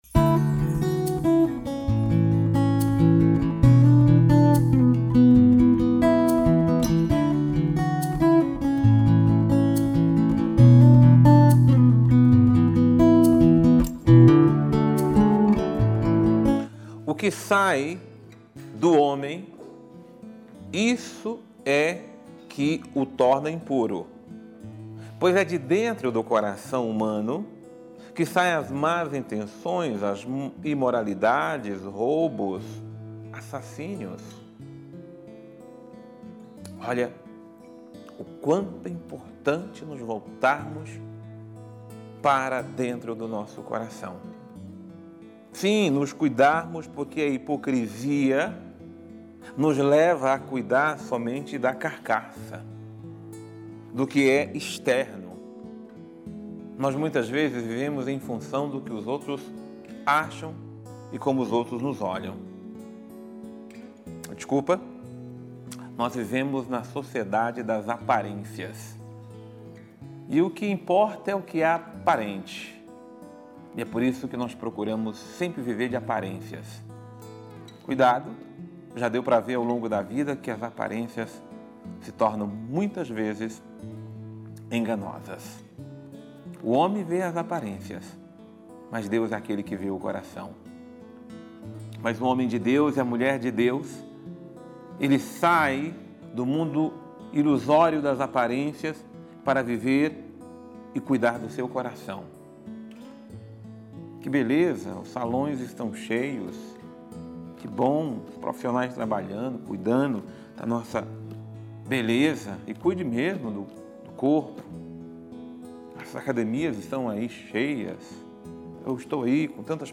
Homilia diária | É essencial cuidarmos do nosso coração